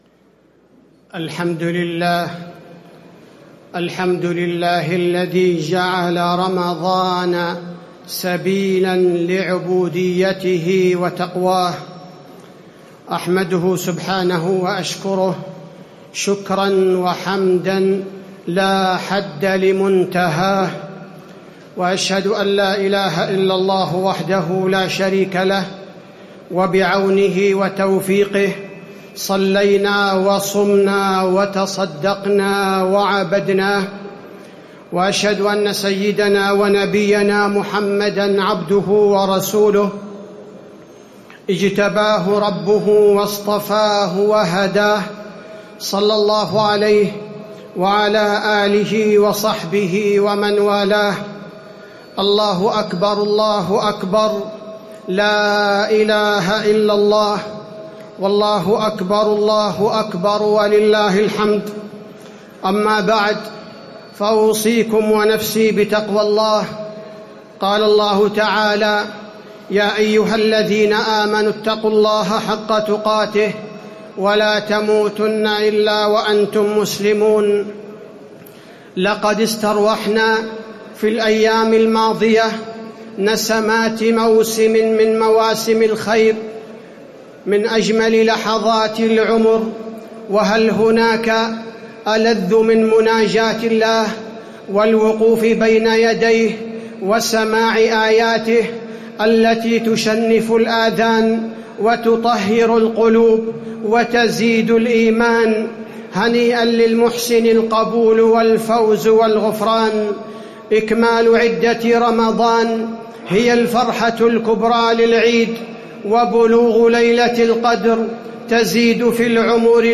خطبة عيد الفطر- المدينة - الشيخ عبدالبارىء الثبيتي
تاريخ النشر ١ شوال ١٤٣٧ هـ المكان: المسجد النبوي الشيخ: فضيلة الشيخ عبدالباري الثبيتي فضيلة الشيخ عبدالباري الثبيتي خطبة عيد الفطر- المدينة - الشيخ عبدالبارىء الثبيتي